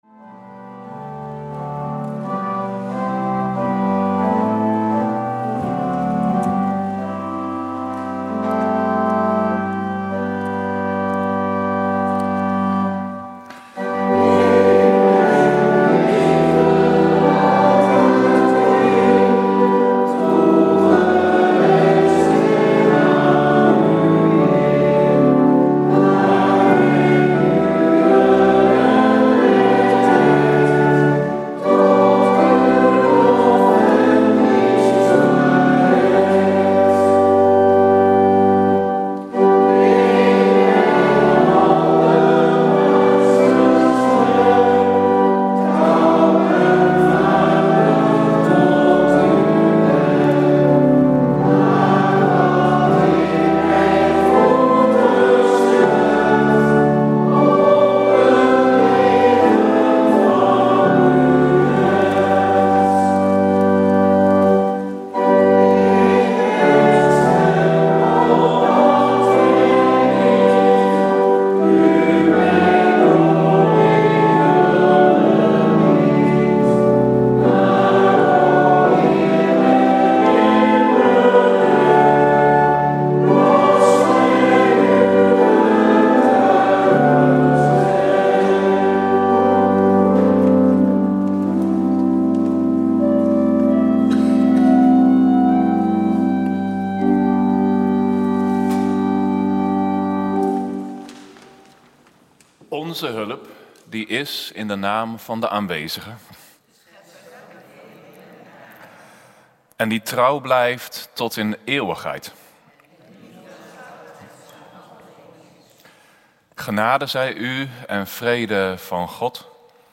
Kerkdiensten
Kerkdienst gemist?